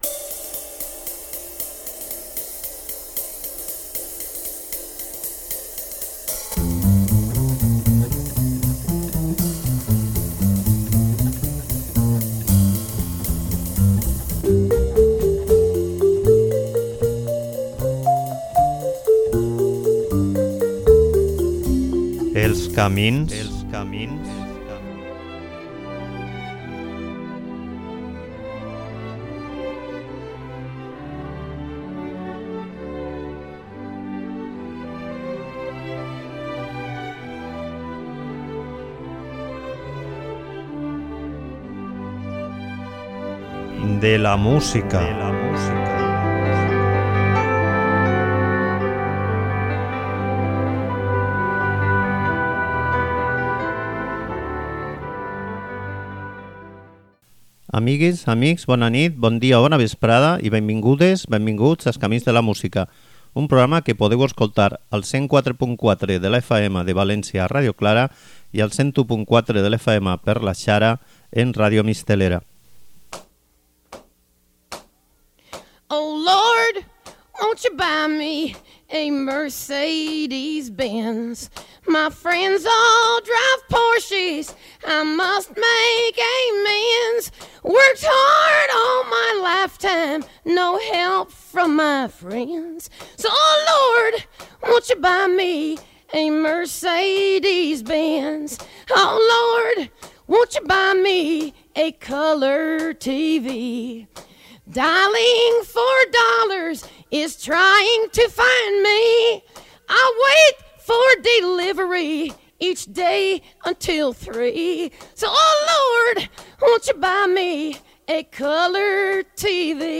La seua veu, rogallosa, trencada, que li eixia de la part més fonda del seu cos, que no pas de la gola, s'escolta amb tot el nostre cos -no sols amb l'oïda- i amb una presència escènica que et deixa enganxat de la seua expresiva imatge, on aboca totes les vivències, bones i roïnes, de la seua enbogida i breu vida.